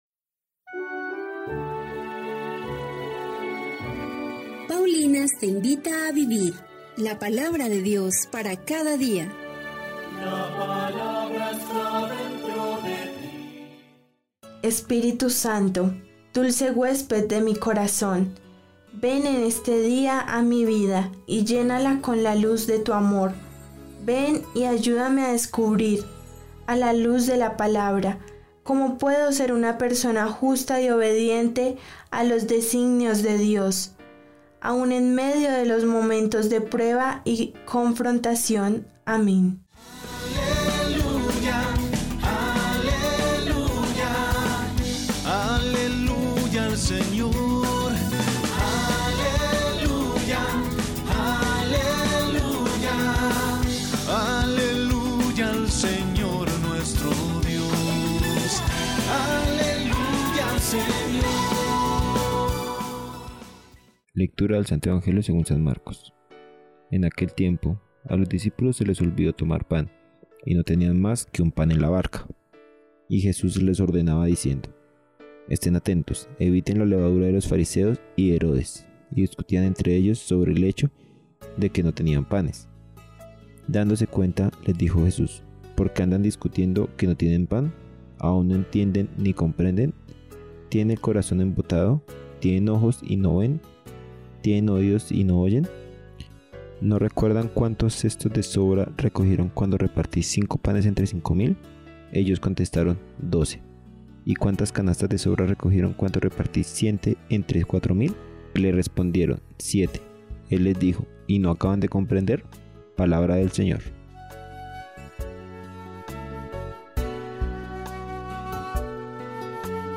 Lectura del libro del Génesis 9, 8-15